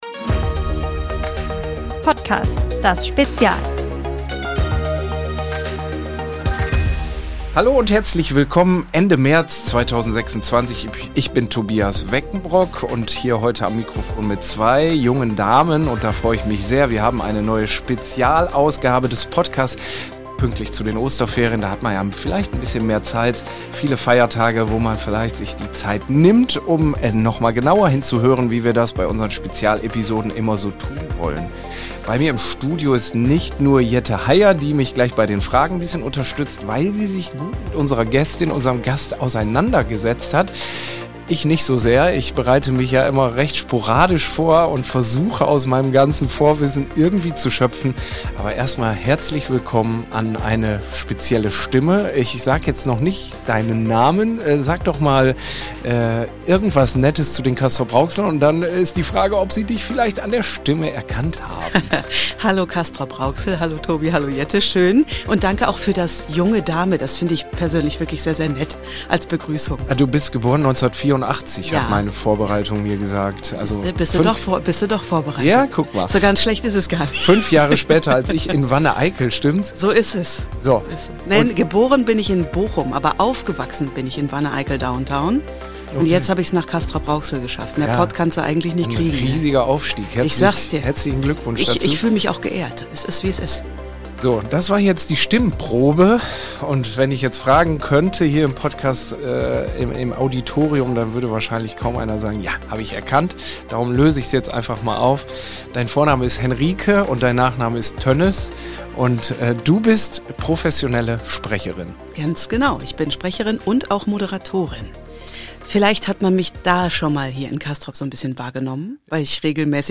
Warm, tief, leicht angeraut, sagt sie selbst.